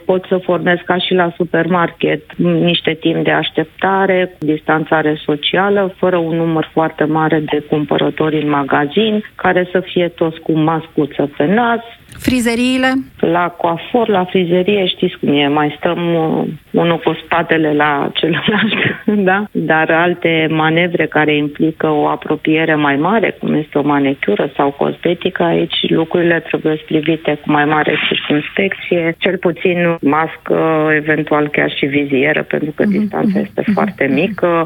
Frizeriile ar putea primi din nou clienți, cu programări, pentru ca nu le va fi permis sa aștepte în interior, a explicat la Europa Fm Adriana Pistol, directoarea Centrului de Supraveghere și Control al Bolilor Transmisibile din cadrul INSP și președinta comisiei Covid-19 din Ministerul Sănătății: